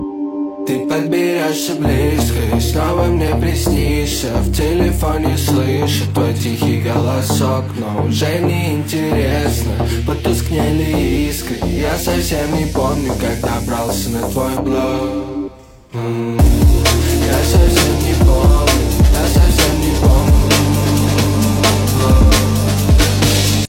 Жанр: Казахские / Русские песни